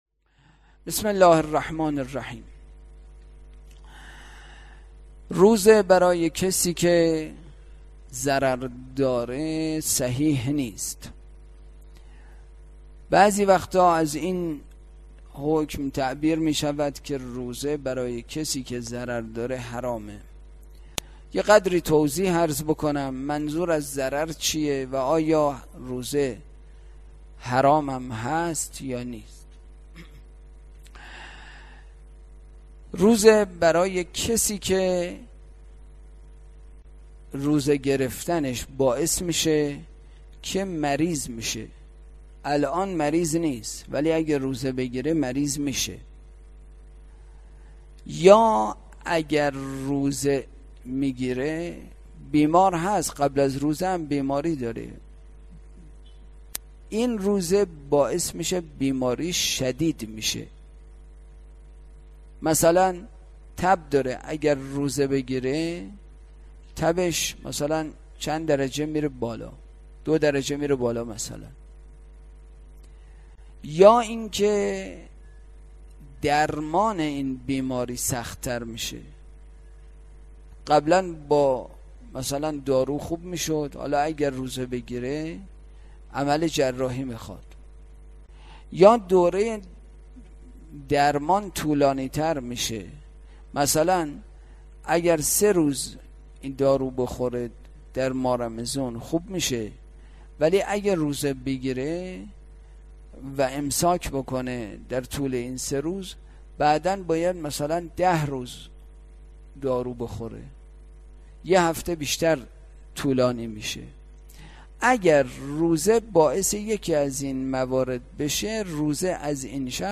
برگزارکننده: مسجد اعظم قلهک